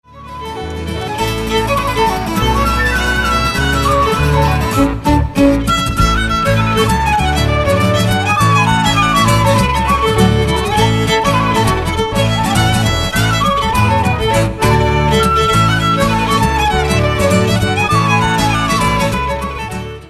Zespół Forann prezentuje repertuar inspirowany etniczną muzyką ludów celtyckich, a w szczególności muzyką Walii, Szkocji, Bretanii i Irlandii.
skrzypce
gitara basowa
akordeon
flet poprzeczny